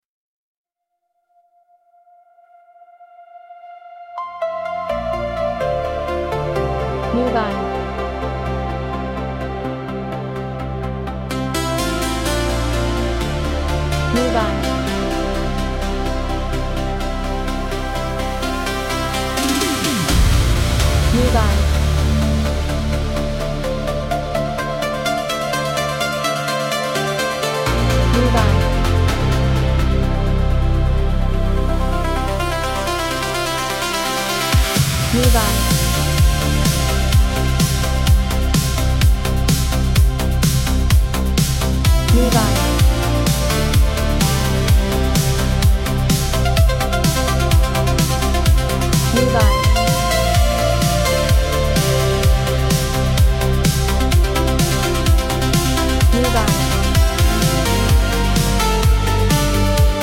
Genre: Futuristic, melodic